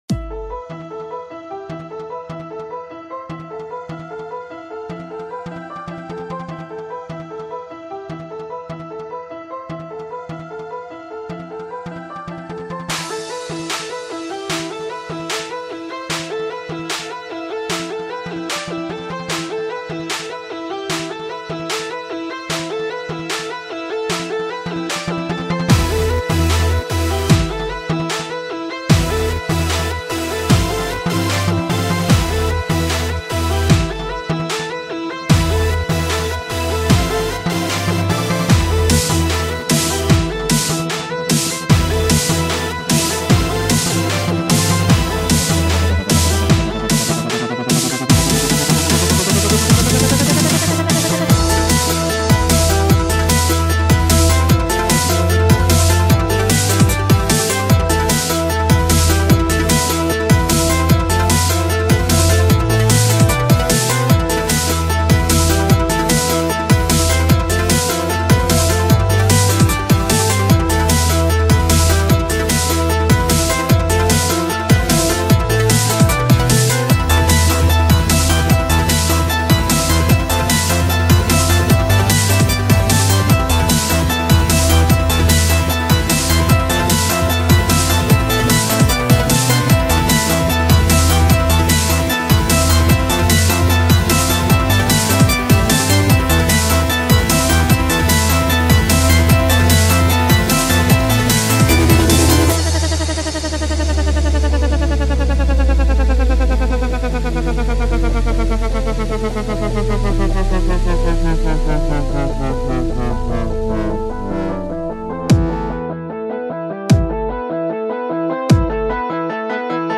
I just had to do a remix of it so here it is!